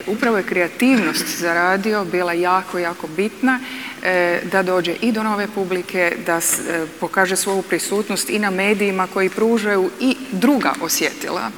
Tim povodom u petak je tradicionalno održan 5. Hrvatski radijski forum, na kojem su vodeći ljudi iz kulturne i kreativne industrije govorili upravo o Radiju i kreativnoj ekonomiji.